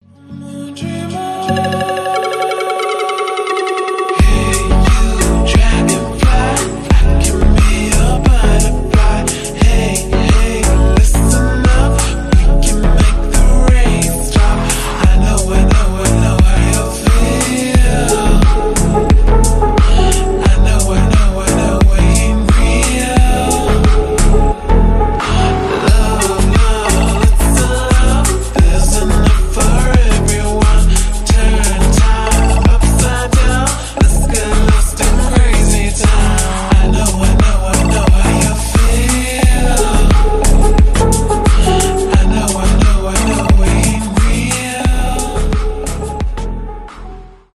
deep house , медленные , чувственные
клубные